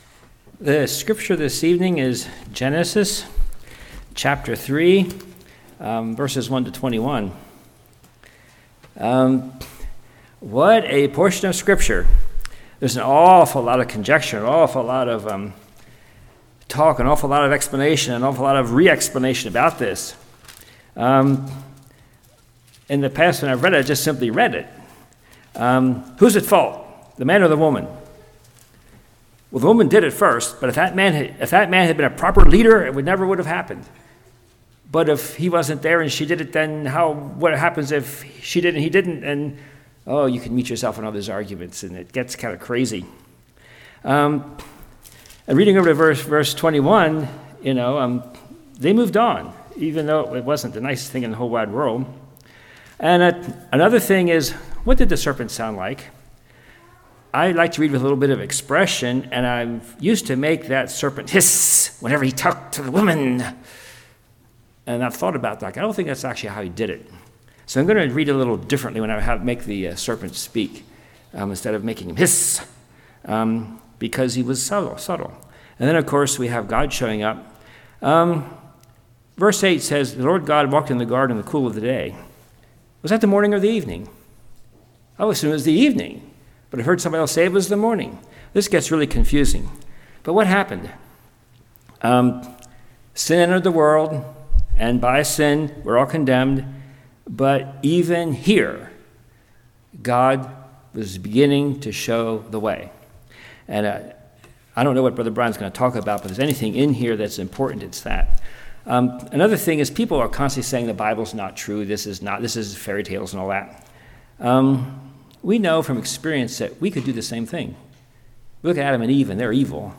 Genesis 3:1-21 Service Type: Evening Their Eyes Where Opened